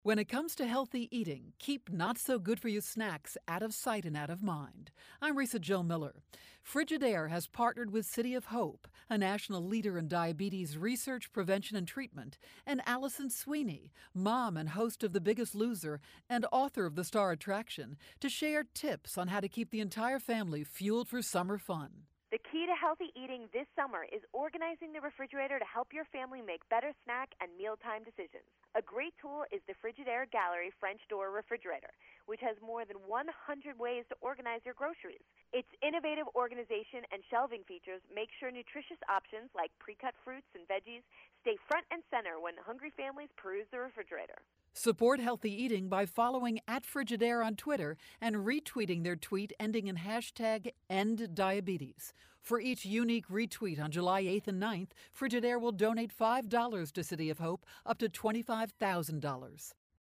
July 8, 2013Posted in: Audio News Release